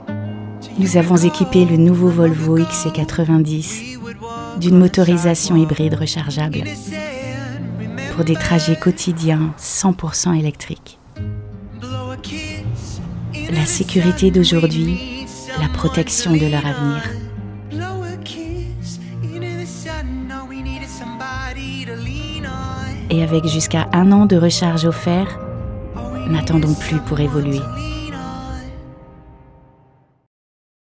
Voix off
Comédienne voix off